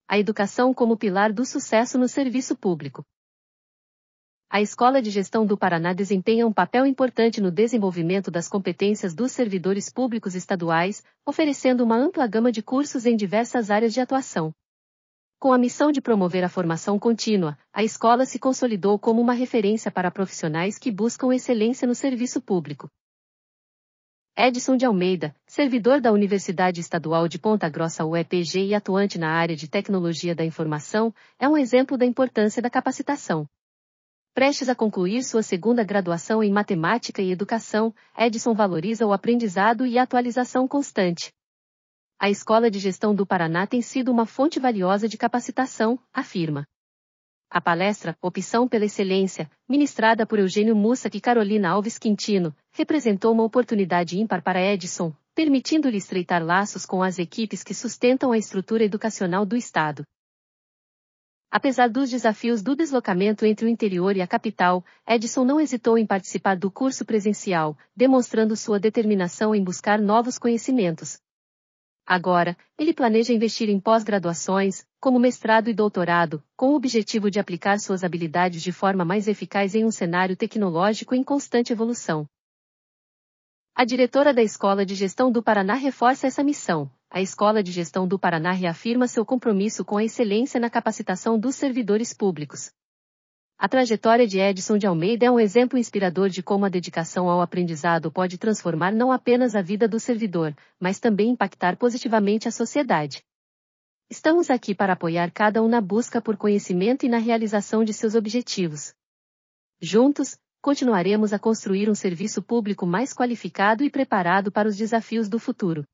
audionoticia_educacao_como_pilar_do_sucesso.mp3